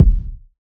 • Urban Kick Single Hit D# Key 192.wav
Royality free kick drum single hit tuned to the D# note. Loudest frequency: 105Hz
urban-kick-single-hit-d-sharp-key-192-3gy.wav